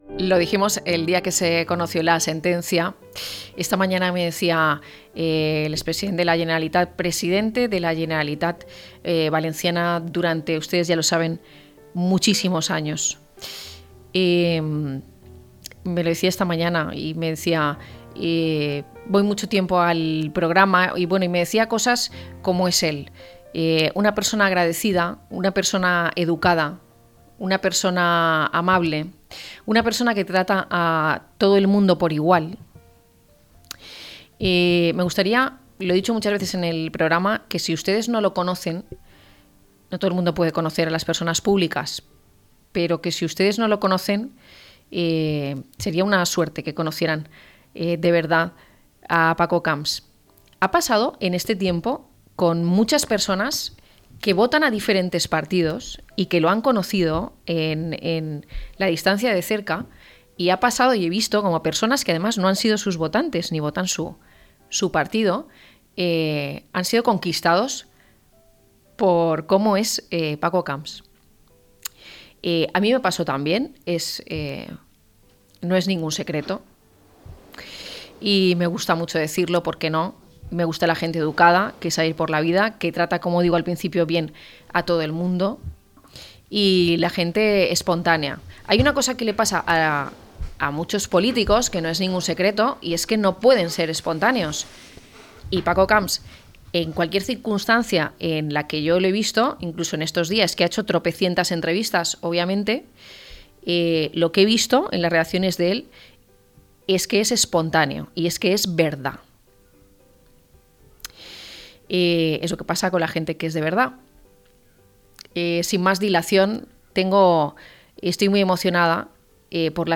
Recibimos en un especial, al ex president de la Generalitat Valencia, Francisco Camps. Camps tiene prisa por volver a la política.